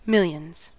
Ääntäminen
Ääntäminen US Tuntematon aksentti: IPA : /ˈmɪl.jənz/ Haettu sana löytyi näillä lähdekielillä: englanti Käännöksiä ei löytynyt valitulle kohdekielelle. Millions on sanan million monikko.